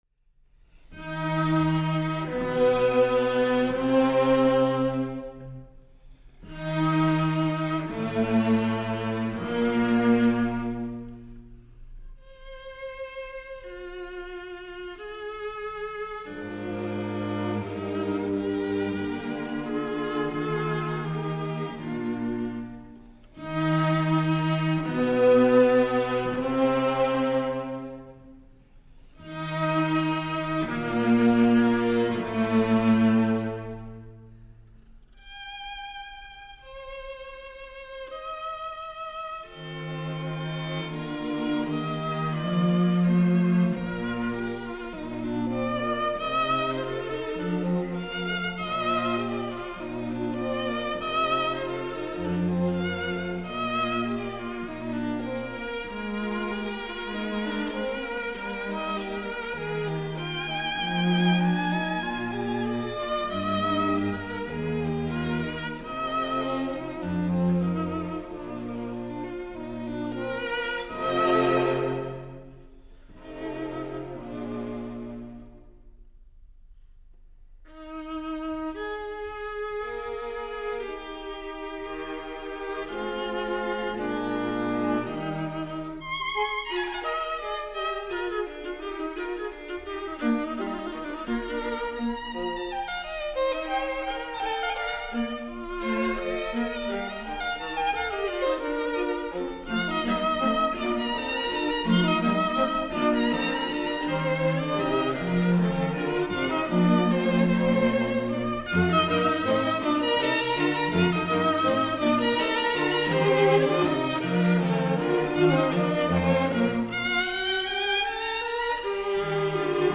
String Quartet in E flat major
Adagio-Allegro assai-Adagio